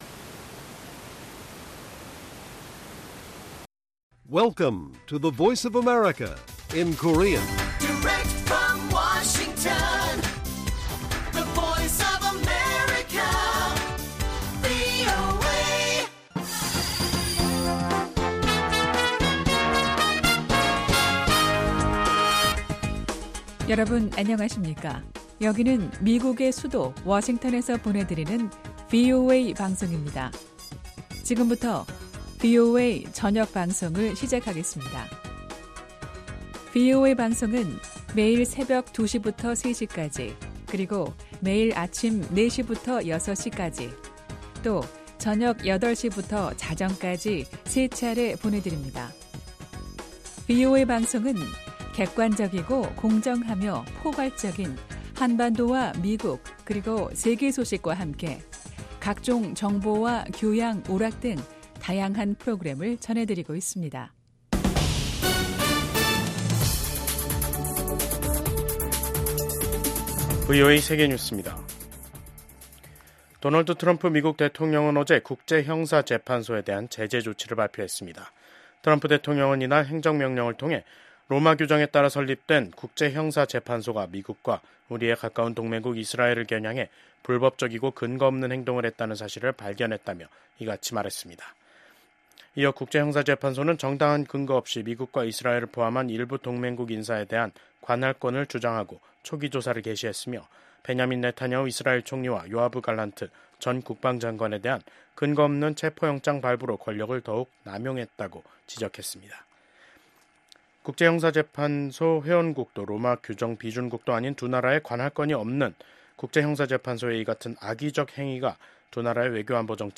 VOA 한국어 간판 뉴스 프로그램 '뉴스 투데이', 2025년 2월 7일 1부 방송입니다. 미국 도널드 트럼프 대통령의 측근인 빌 해거티 상원의원이 미한일 경제 관계는 3국 협력을 지속시키는 기반이 될 수 있다고 강조했습니다. 한국에서 정부는 물론 방산업체 등 민간기업들까지 중국의 생성형 인공지능(AI) 딥시크 접속 차단이 확대되고 있습니다.